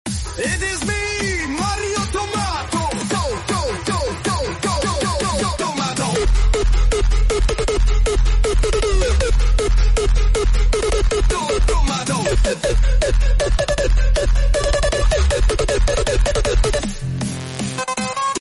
🍅 A tomato-themed Super Mario bouncing along perfectly in sync to a techno beat — the ultimate mix of absurd meme energy and nostalgia.